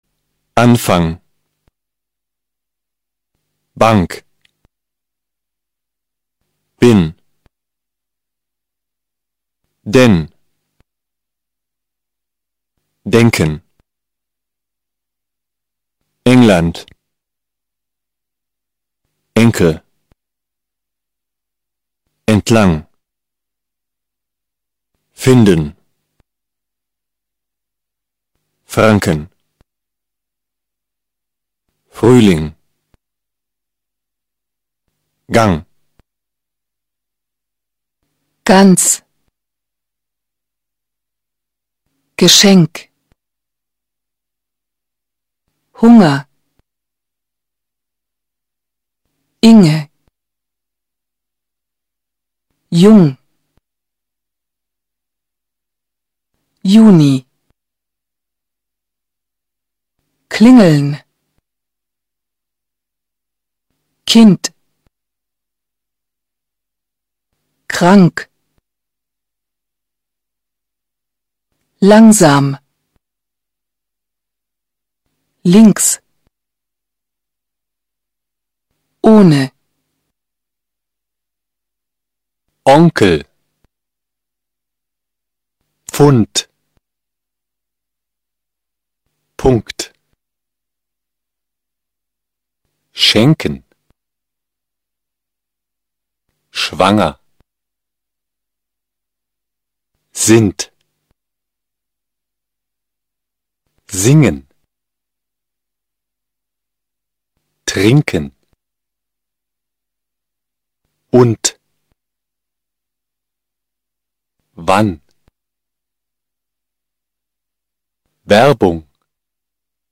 Here you will find practical exercises which will help you learn how to pronounce typical German sounds.
NG, NK, N
ng-nk-nn.mp3